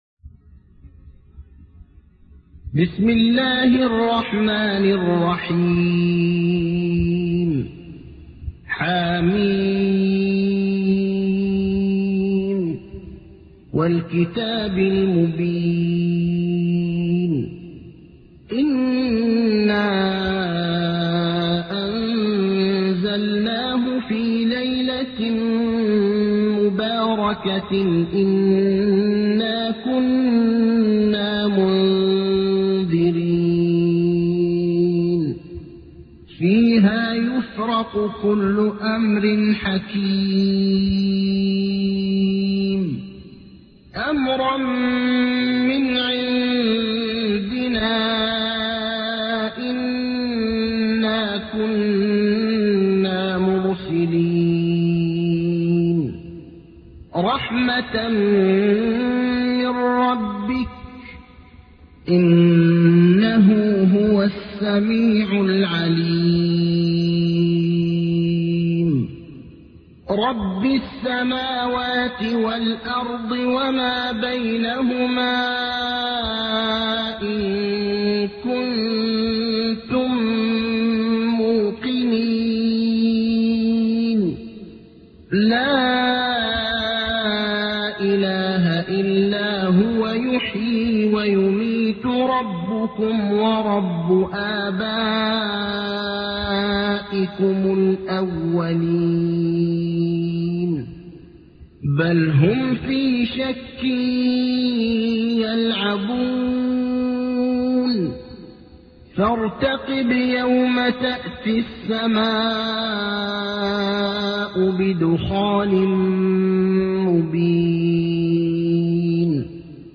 تحميل : 44. سورة الدخان / القارئ ابراهيم الأخضر / القرآن الكريم / موقع يا حسين